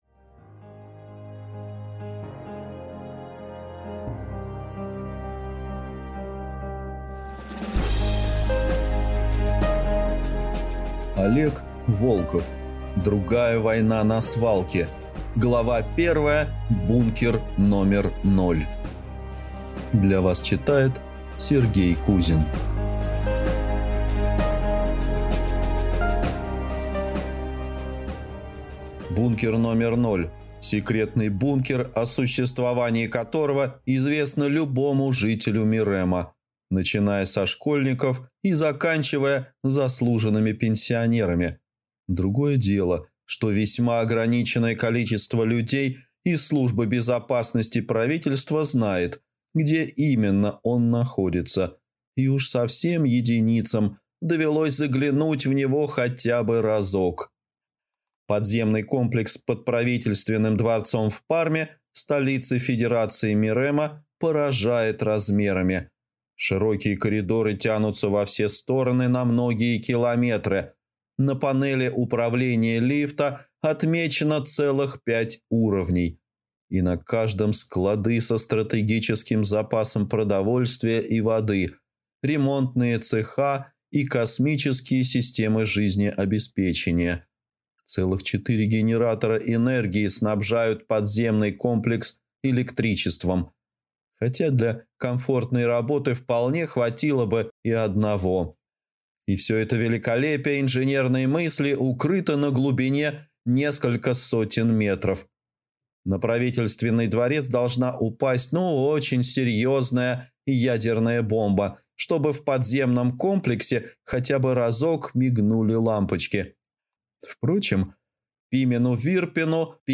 Аудиокнига Другая война на Свалке | Библиотека аудиокниг